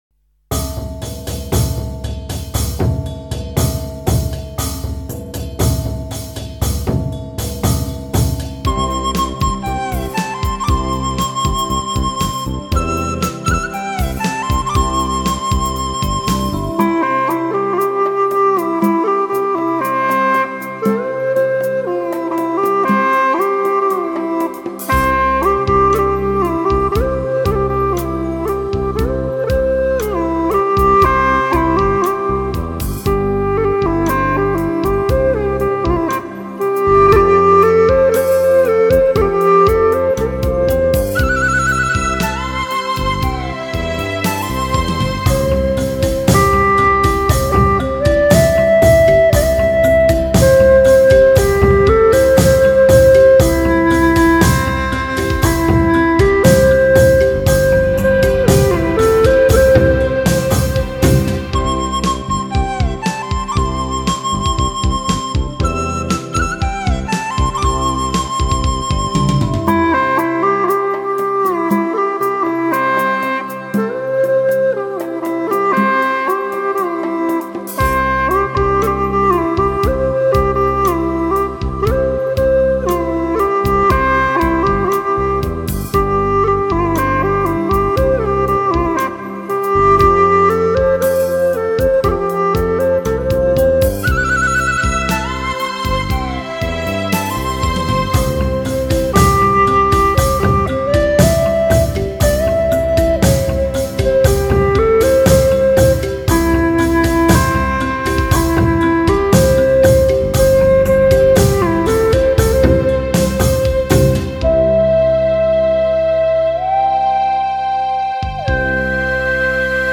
这个专辑虽然都是老曲子，不过都是经过重新编配的，伴奏和音质都非常棒，演奏得非常有味道，呵呵。